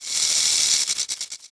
naga_warrior_walk.wav